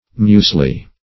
(m[=u]s"l[-e] or m[=u]z"l[-e])